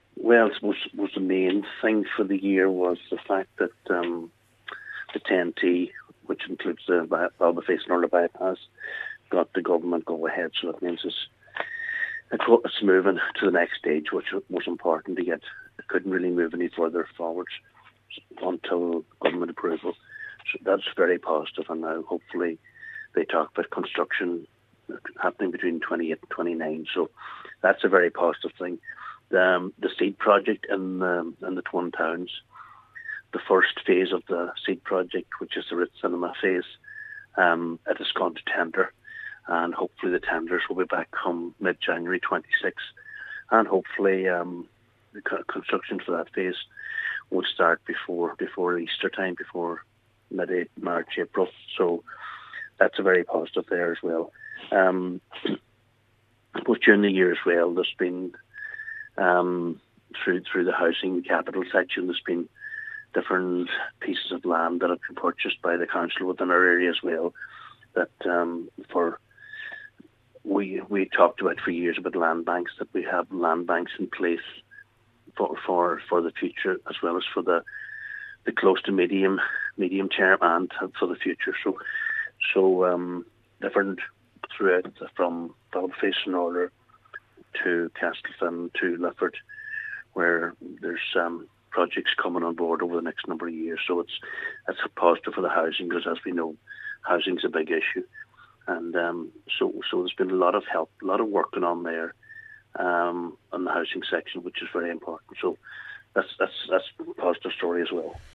Speaking to Highland Radio News, Cllr Harley reflected on progress made on major projects, most notably the Twin Towns Bypass under the TEN-T project.
Cllr Harley says he is pleased to see the project moving forward: